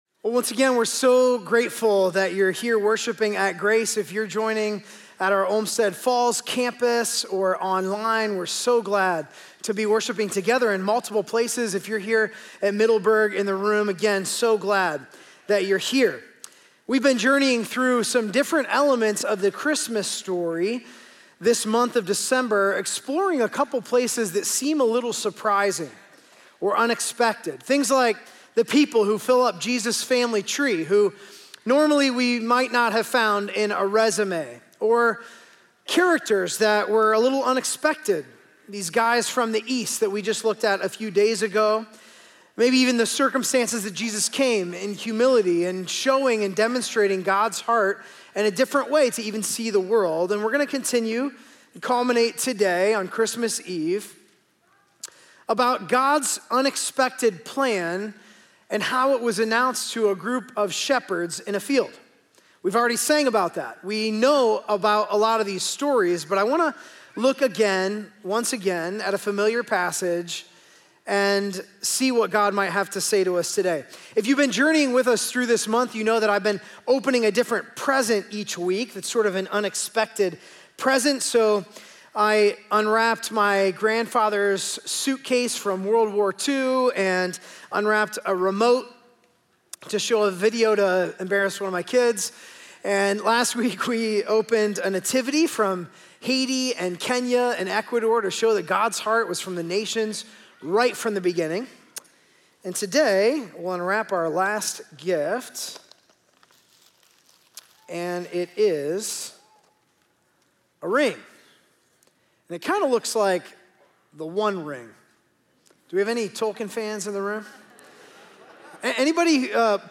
In this Christmas Eve message, we look at the familiar story from Luke 2 and the unexpected responses of the shepherds—moving from fear, to curiosity, to joy. We’re reminded that Jesus didn’t just come as a king, but as a sacrifice, and that the message of Christmas is deeply personal.